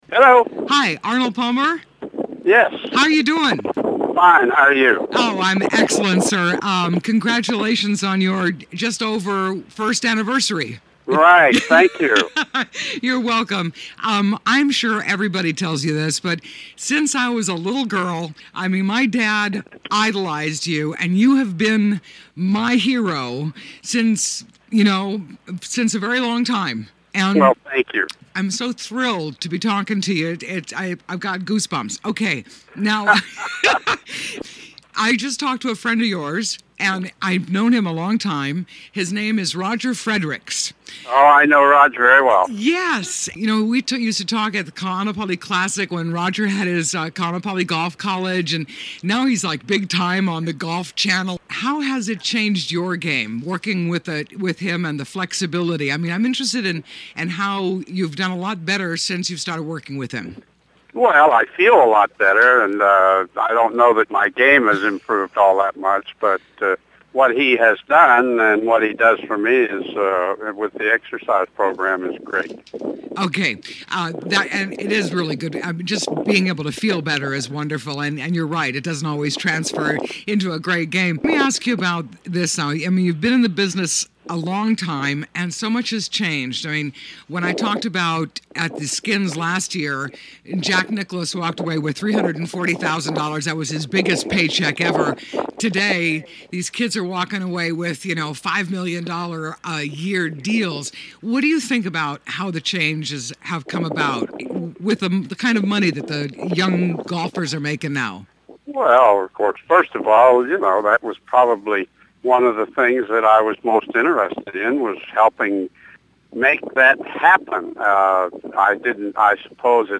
Walking the Wailea Golf Course.
Arnold Palmer Int 2-06.mp3